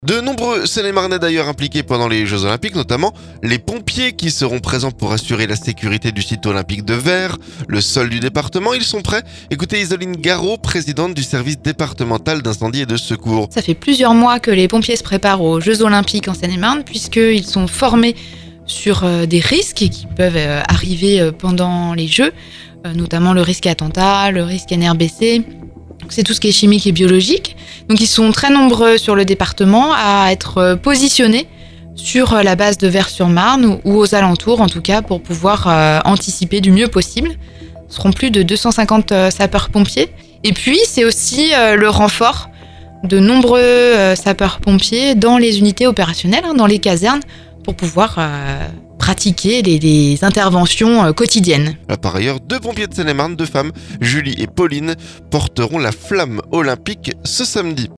Isoline Garreau, présidente du Service départemental d'incendie et de secours.